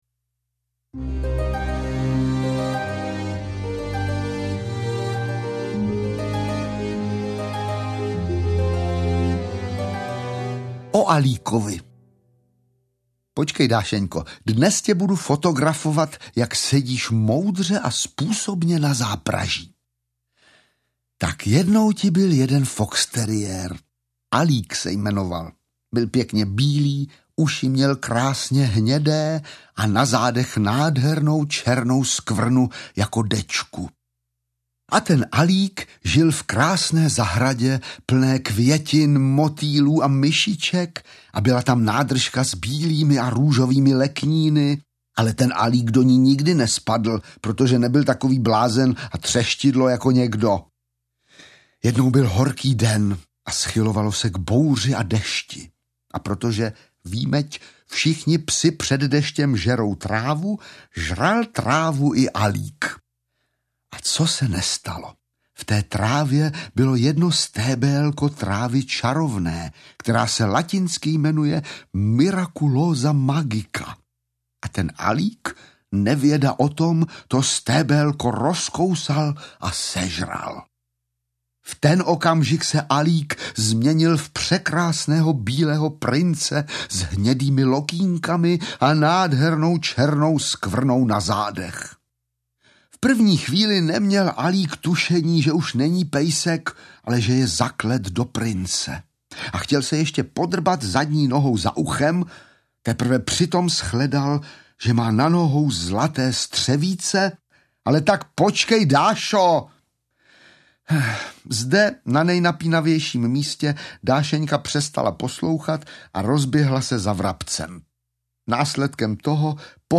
Dášeňka čili život štěněte audiokniha
Ukázka z knihy
• InterpretVáclav Vydra ml.